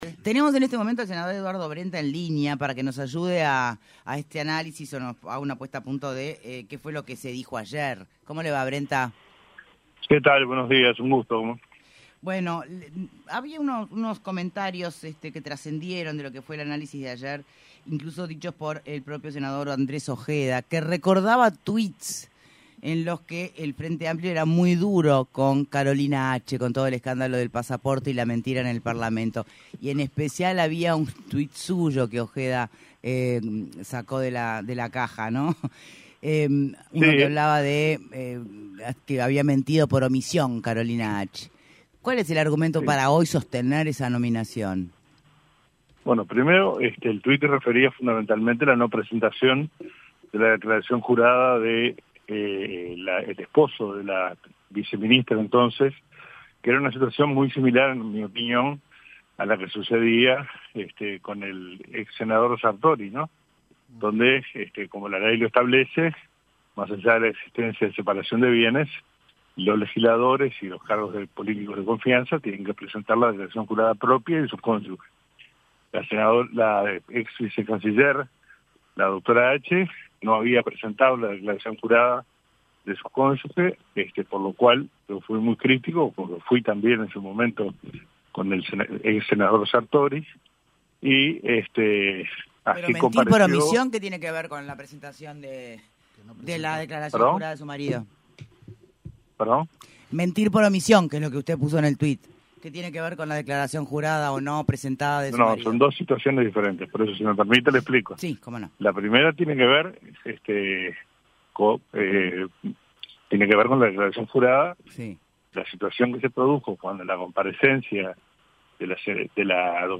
En entrevista con Punto de Encuentro, el senador del Frente Amplio, Eduardo Brenta, explicó los cambios que tuvo el proyecto de ley que establece que los ingresos de funcionarios a las intendencias sean por concurso y ahora se habilita que 4% sean por designación directa, pero que deberán irse cuando termina el mandato del intendente.